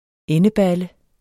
Udtale [ ˈεnəˌbalə ]